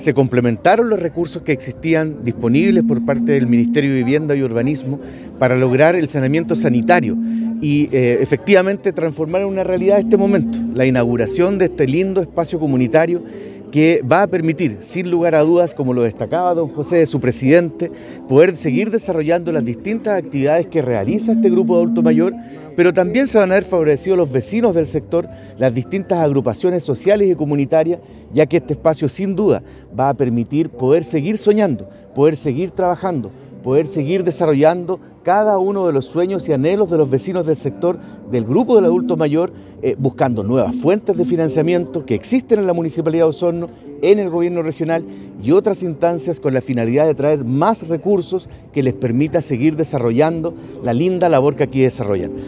Por otra parte, el Alcalde (S) Claudio Villanueva enfatizó en que desde la Casa Edilicia buscan entregar los espacios adecuados a las personas mayores, para permitir su participación en los distintos aspectos del quehacer social de Osorno.